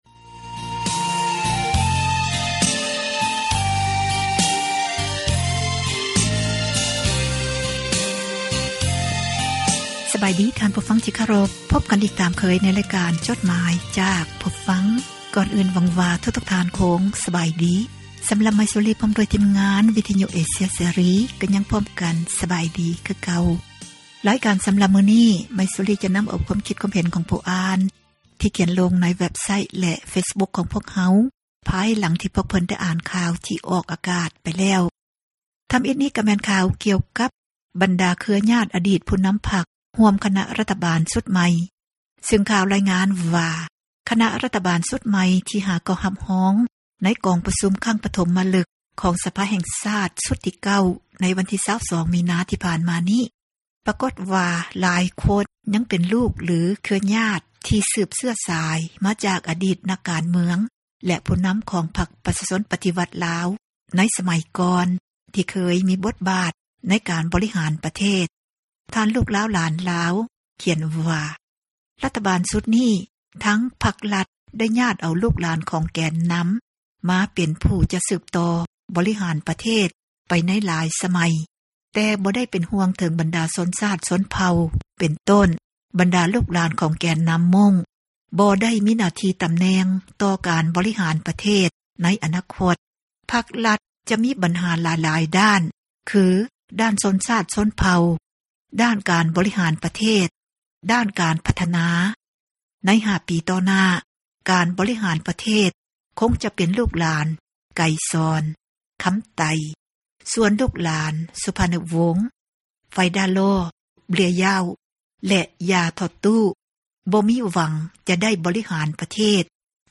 ອ່ານຈົດໝາຍ, ຄວາມຄຶດຄວາມເຫັນ ຂອງທ່ານ ສູ່ກັນຟັງ